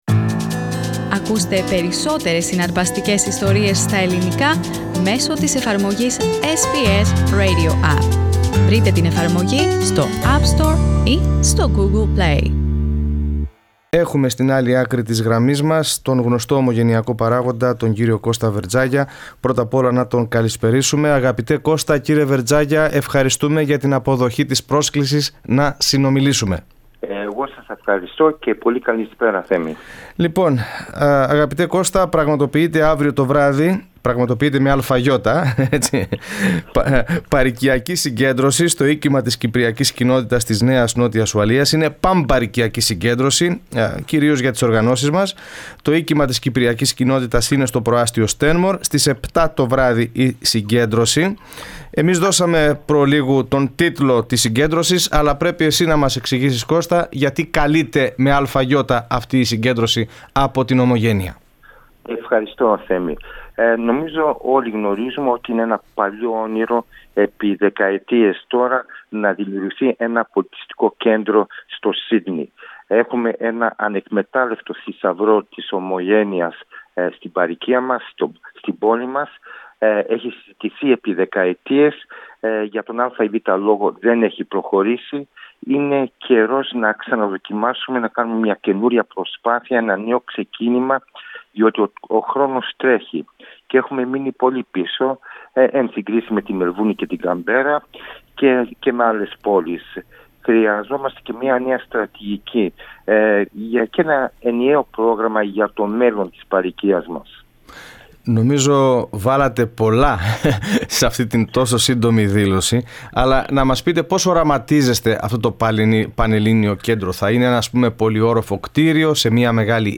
in an interview with SBS Greek Program.